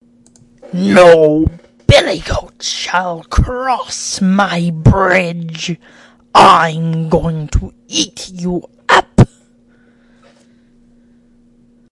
蹄子
描述：山羊蹄穿过一座桥。
声道立体声